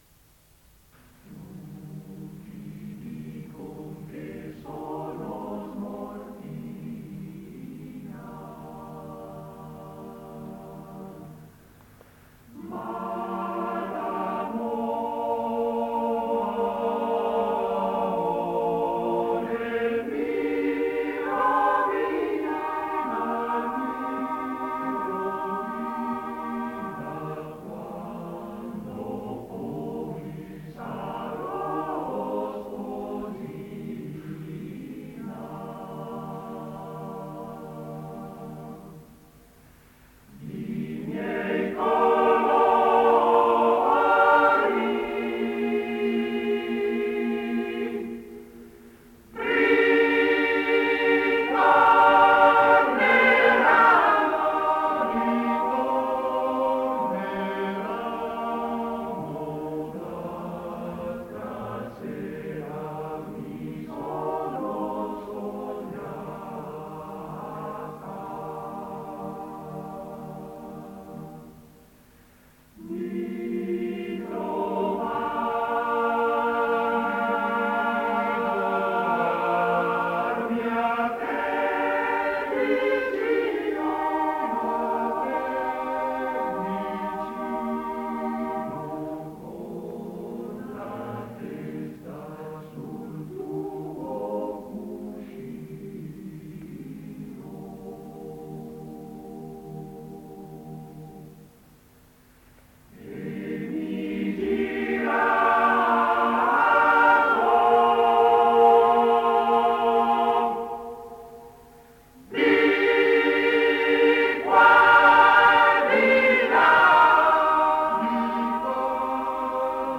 Arrangiatore: Pigarelli, Luigi (Armonizzatore)
Esecutore: Coro CAI Uget